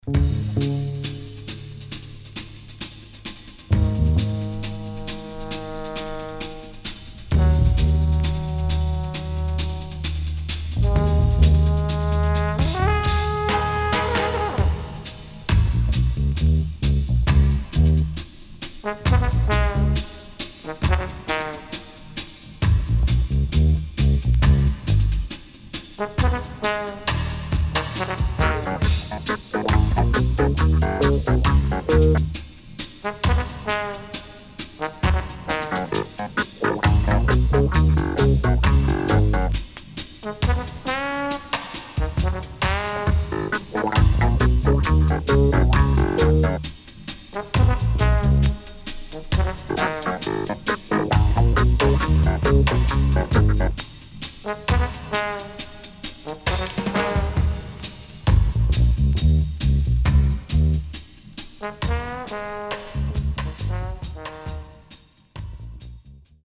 HOME > Back Order [VINTAGE 7inch]  >  KILLER & DEEP
のSeriousなメッセージとファルセットヴォイスが胸を打つ傑作曲
SIDE A:所々チリノイズがあり、少しプチノイズ入ります。